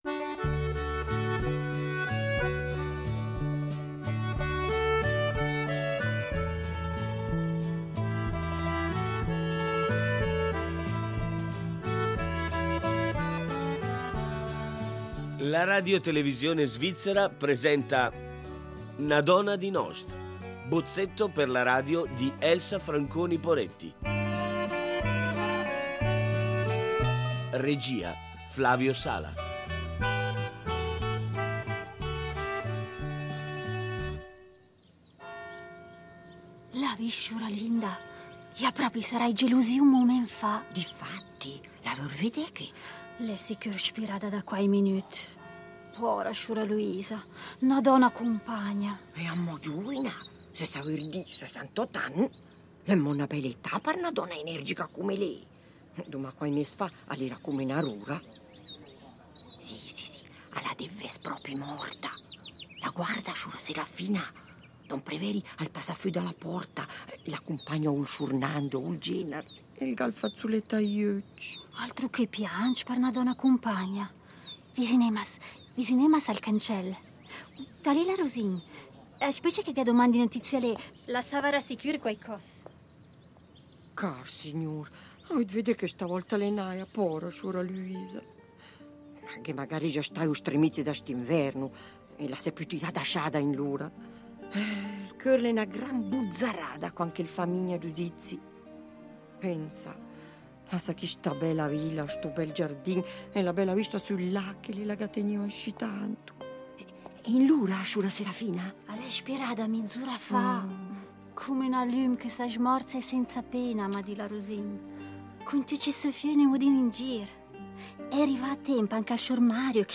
Bozzetto per la radio di Elsa Franconi Poretti
viene raccontata alternando scene in presa diretta a narrazioni fatte in prima persona dalla protagonista
Quadri di vita semplice, in una Lugano d’altri tempi, scritti in un dialetto ricco e filologicamente interessante che si fa testimonianza dell’idioma caratteristico di Lugano dove l’incontro tra la lingua del popolo e quella del cittadino permette interessanti osservazioni legate alla società, agli usi e costumi del tempo. Gli interpreti di questa produzione sono un mix di esperienza e gioventù, voci tra le più riconoscibili del teatro dialettale radiofonico